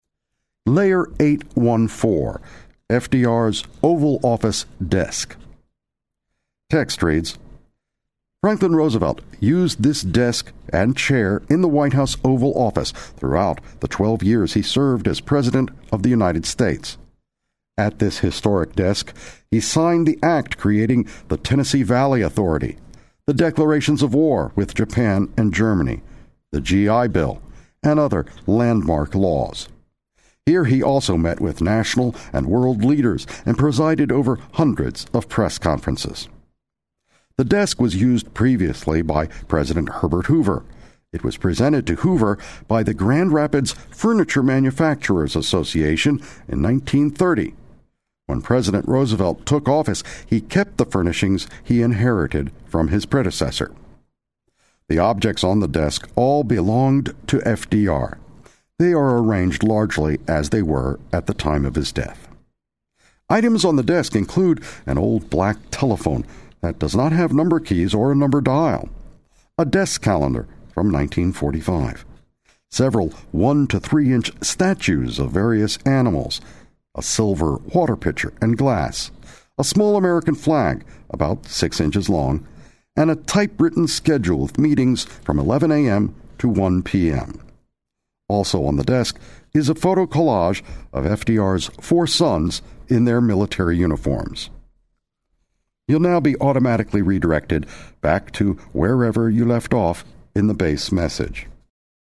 Audio Description (AD) is the narration of the visual elements of a space. Our AD tour has over 13 hours of narration, which includes the permanent exhibit text as well as verbal illustrations of exhibit spaces, documents, artifacts, and interactives.